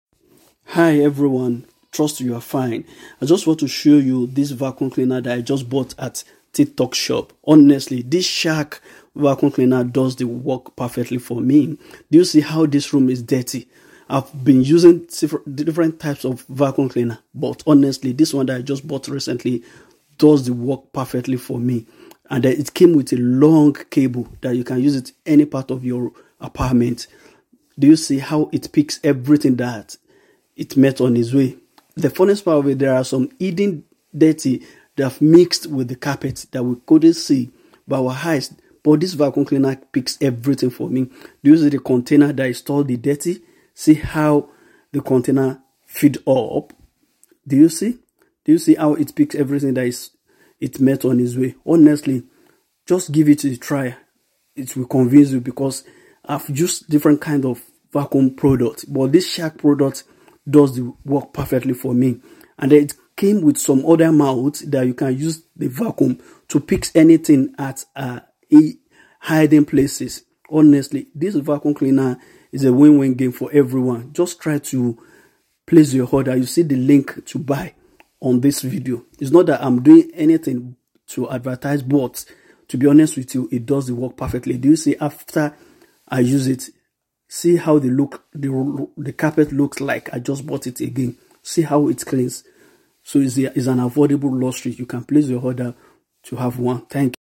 SHARK CORDED UPRIGHT VACUUM CLEANER sound effects free download